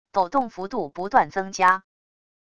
抖动幅度不断增加wav音频